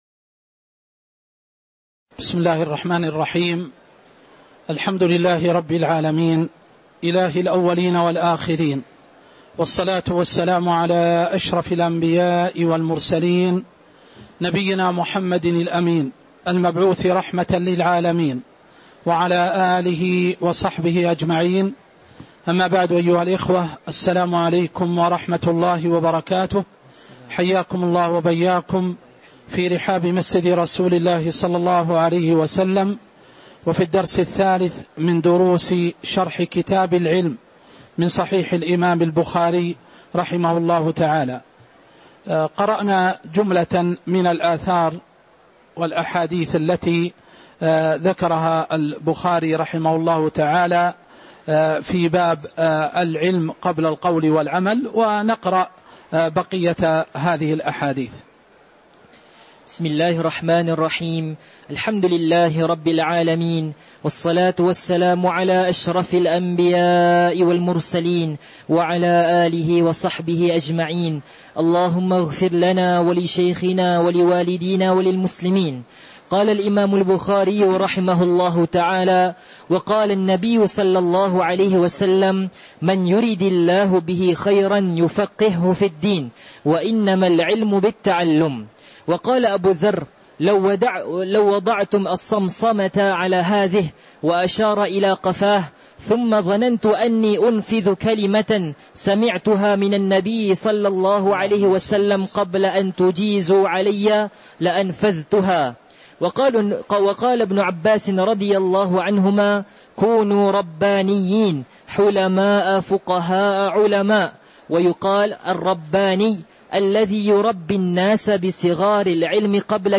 تاريخ النشر ٩ محرم ١٤٤٦ هـ المكان: المسجد النبوي الشيخ